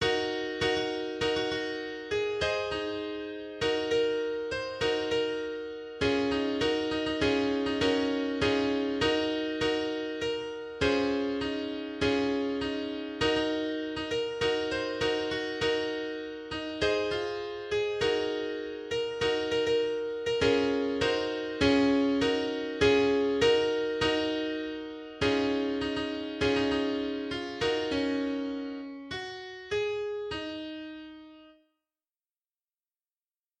Those that are just a piano are the MIDI processed through LMMS without any fiddling with the instruments.